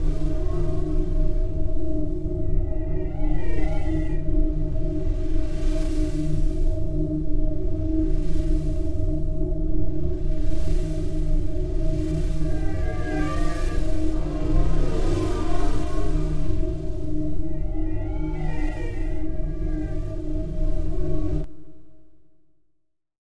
Sci-Fi Ambiences
AFX_NEBULAFIELD_DFMG.WAV